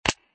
chupai_eff.mp3